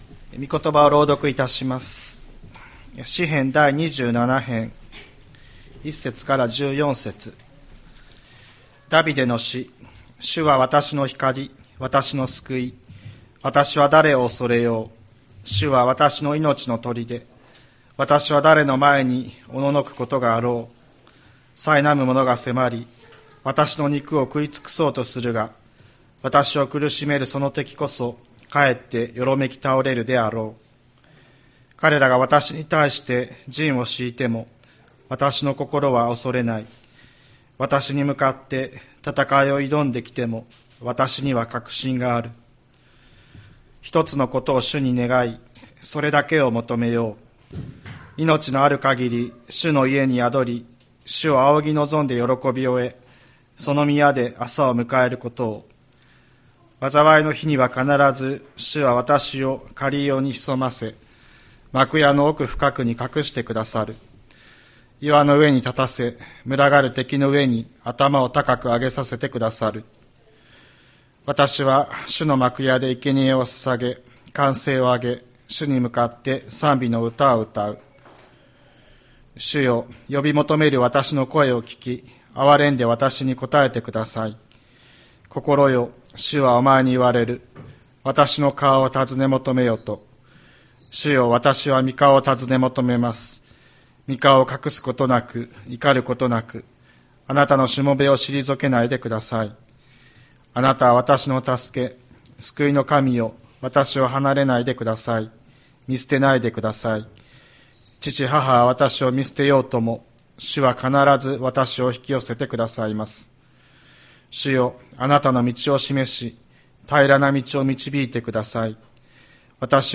2024年01月28日朝の礼拝「ひとつのことだけを求めよう」吹田市千里山のキリスト教会
千里山教会 2024年01月28日の礼拝メッセージ。